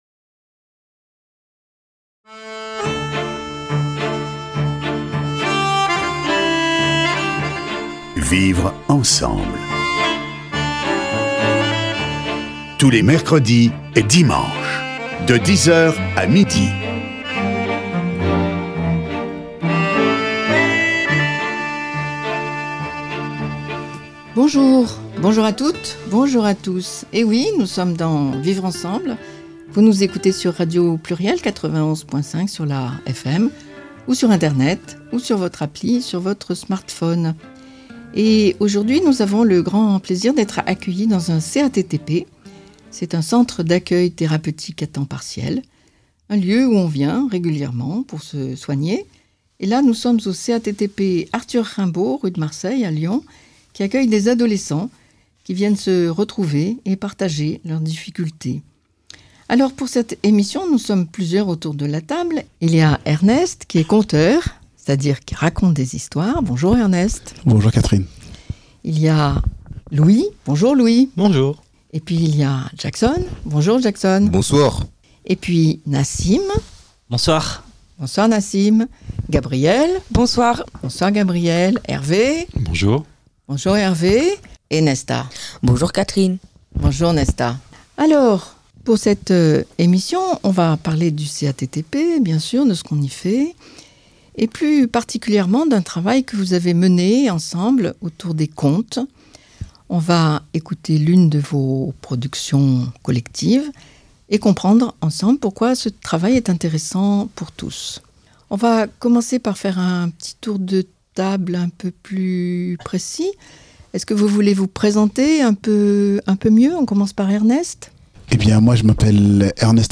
Emission Vivre Ensemble – réalisée au CATTP Arthur Rimbaud, le 3 octobre 2025